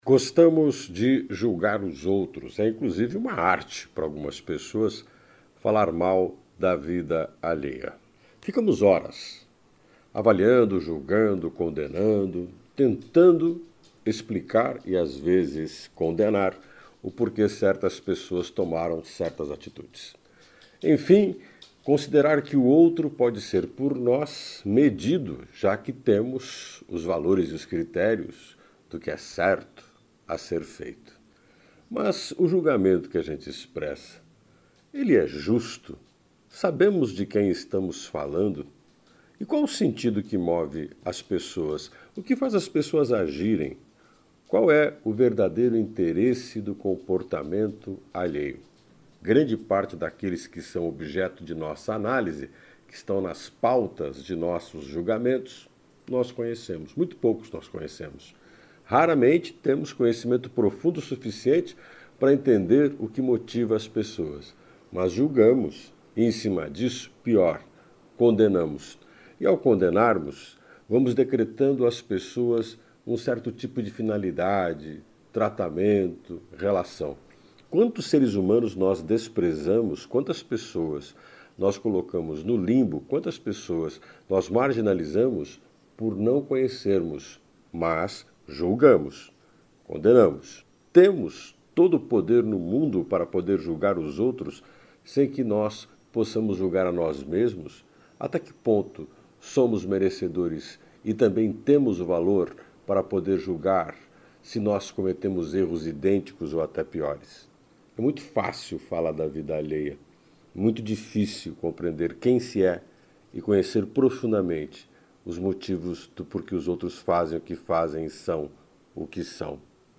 O comentário